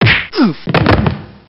دانلود آهنگ مشت زدن 1 از افکت صوتی انسان و موجودات زنده
دانلود صدای مشت زدن 1 از ساعد نیوز با لینک مستقیم و کیفیت بالا
جلوه های صوتی